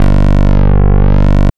Index of /90_sSampleCDs/Trance_Explosion_Vol1/Instrument Multi-samples/Wasp Bass 1
G1_WaspBass_1.wav